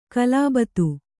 ♪ kalābatu